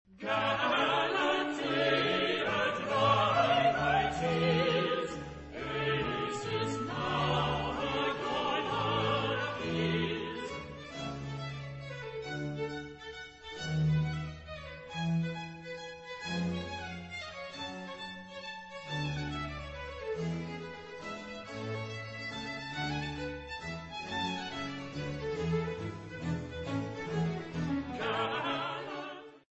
Coro finale,